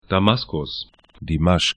Pronunciation
Damaskus da'maskʊs Dimashq di'maʃk ar Stadt / town 33°30'N, 36°18'E